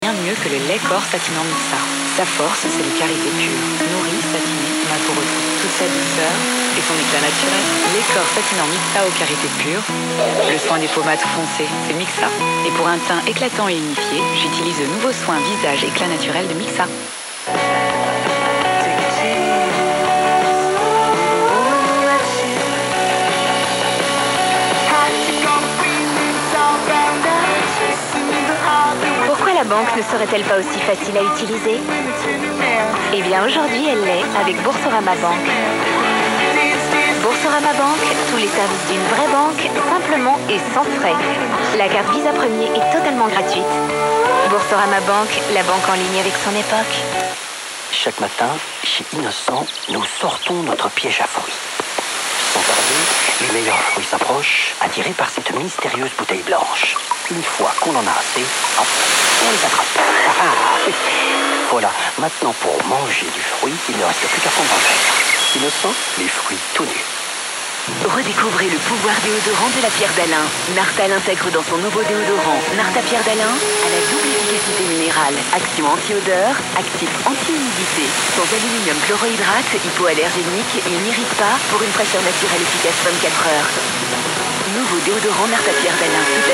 FRENCH TV SOUND ON 54MHZ AM:
Logged the other day, and also today, though todays signals were scrambled.